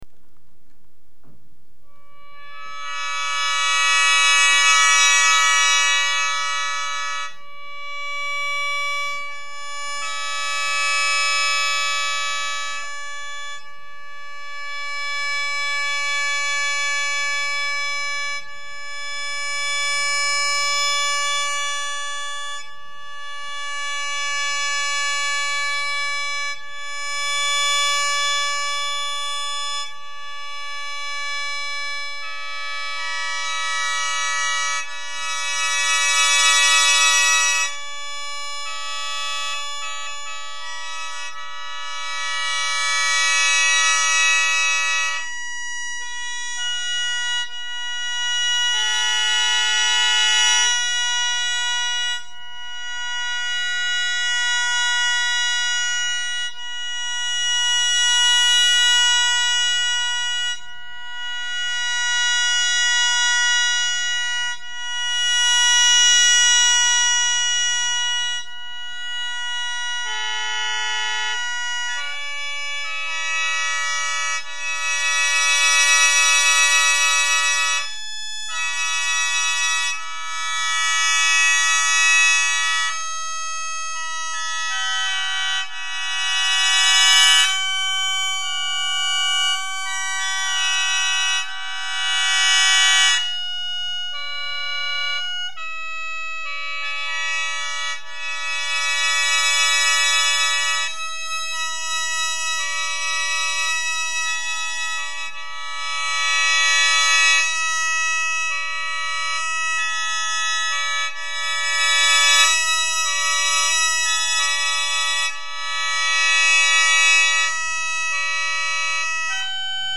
1998年に慶応SFCのスタジオで録音した
アドリブで約3分間ほど演奏した
このサウンド をずっと連続して聞き続けるというのはある意味でなかなかな苦行であり、隣室の奥さんからは「雅楽というよりは新興宗教みたい」とのコメントが届いたが、いくら好き好んだ僕でも、これを続けるのは最大でも1日で3時間が限度なのだ。